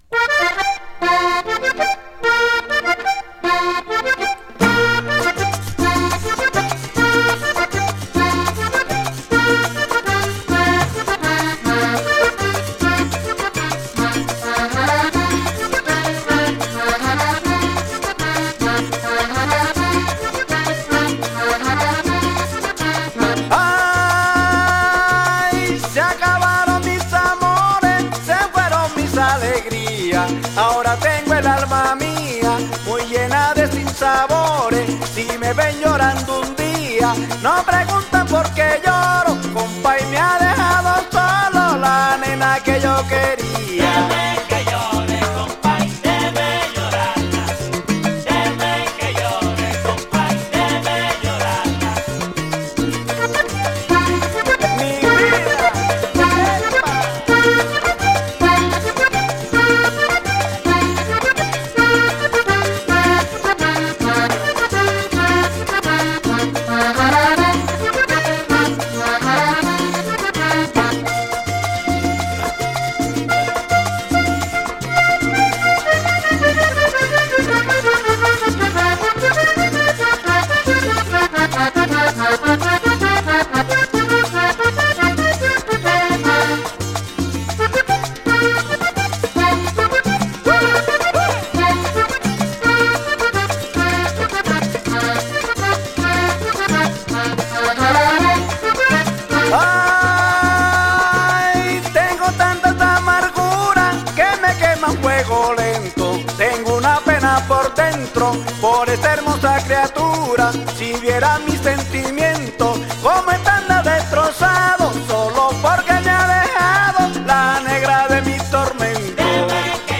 accordion heavy cumbia
These songs can really get the dance floor going.